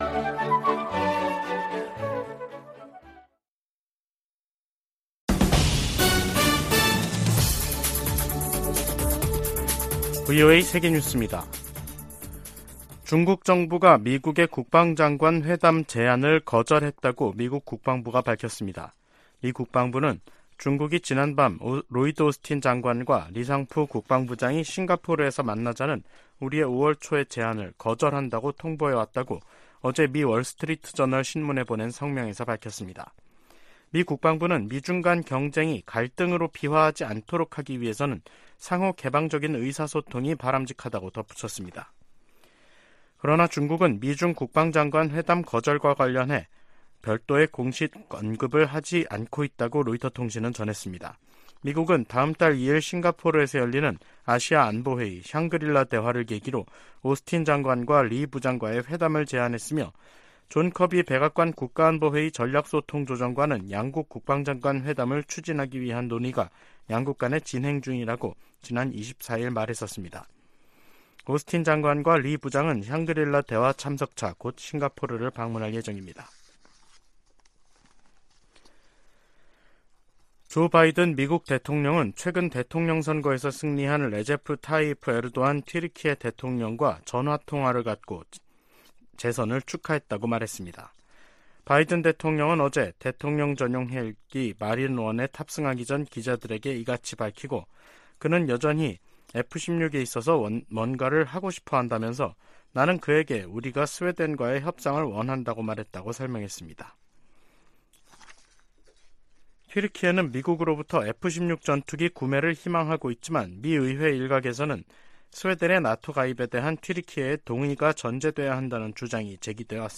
VOA 한국어 간판 뉴스 프로그램 '뉴스 투데이', 2023년 5월 30일 2부 방송입니다. 북한의 동창리 서해 위성발사장에서 로켓을 최종 장착시키는 역할을 하는 조립 건물 2개 동이 발사대 쪽으로 이동한 모습이 관찰됐습니다.